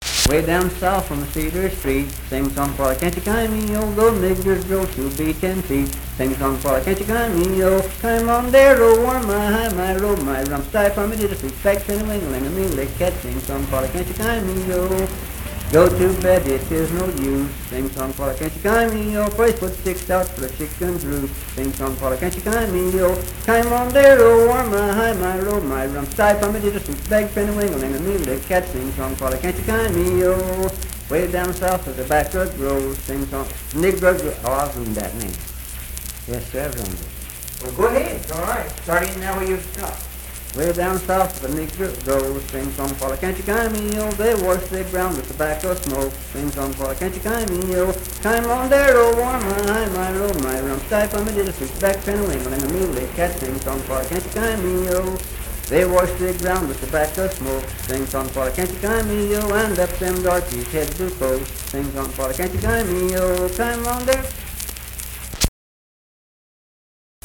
Unaccompanied vocal music performance
Minstrel, Blackface, and African-American Songs
Voice (sung)